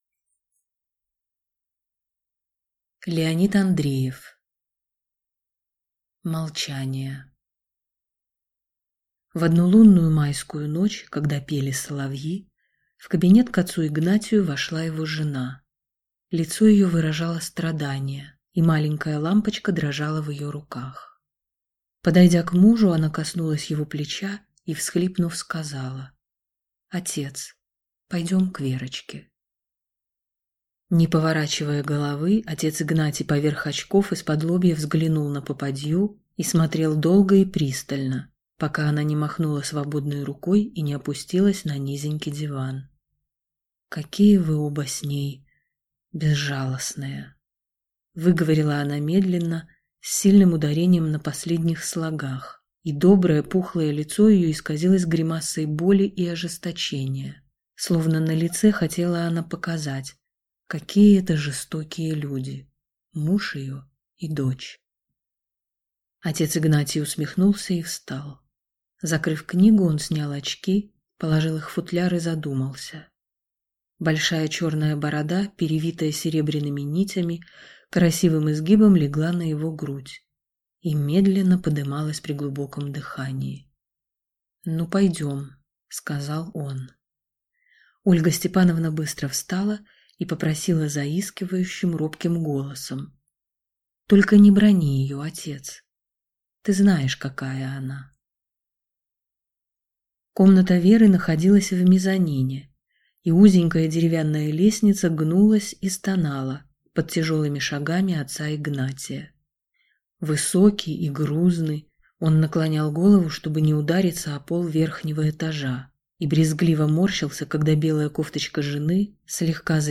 Аудиокнига Молчание | Библиотека аудиокниг